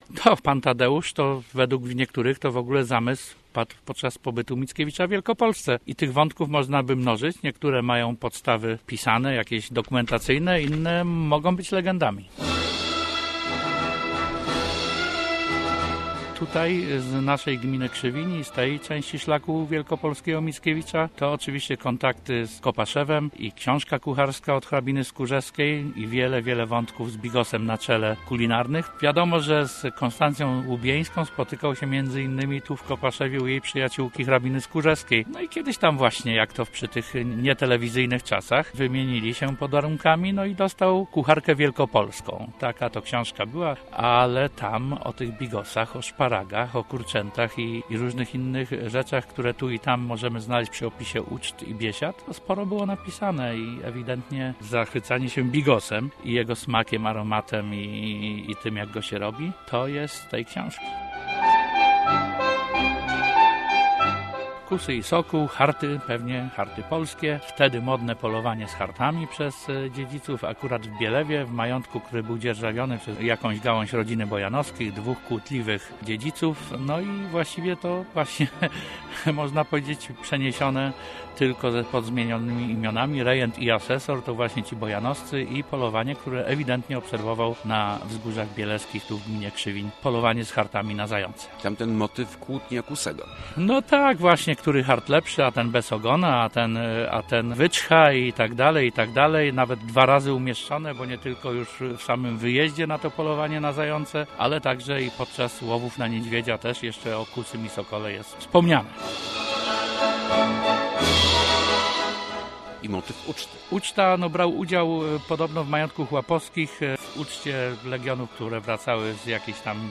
Kilku poznaniaków zdecydowało się przeczytać osobiście fragmenty "Pana Tadeusza" na placu Mickiewicza. Włączyli się w Narodowe Czytanie tego dzieła ogłoszone przez Prezydenta RP.